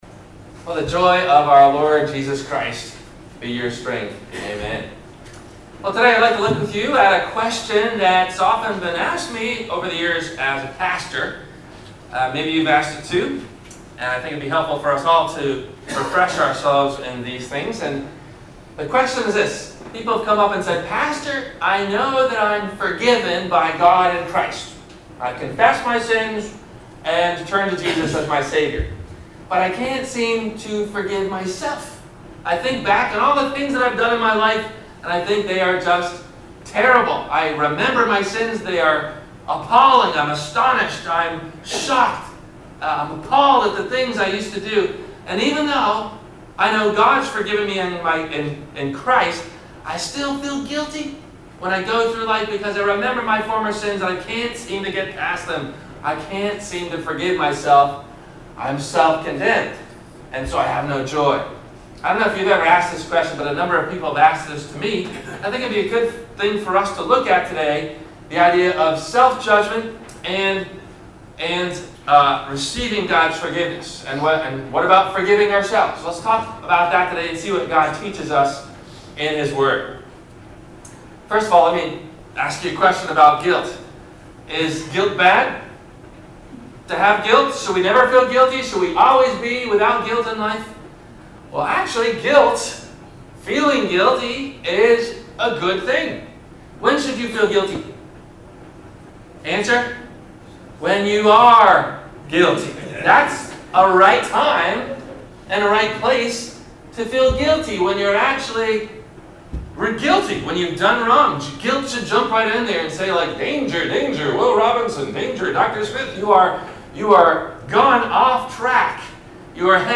Scriptures below from the church bulletin used in the Sermon: